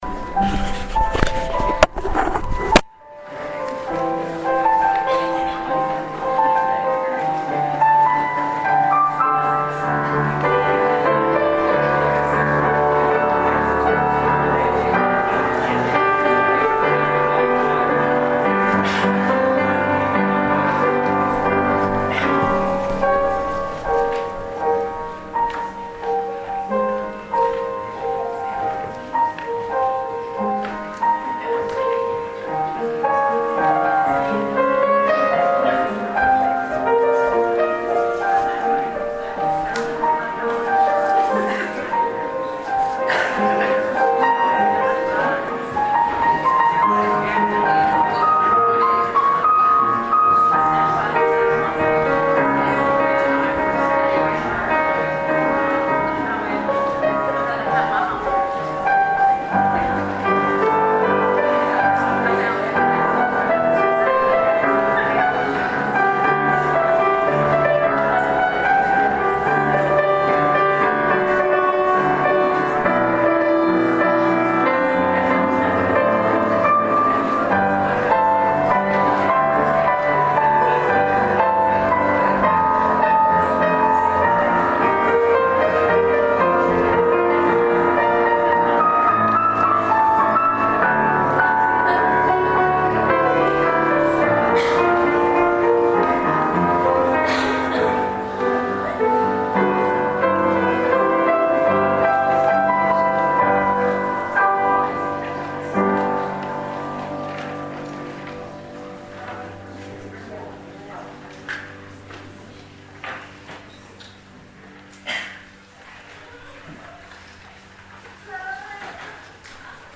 MA Baccalaurette Service LUMC audacity.mp3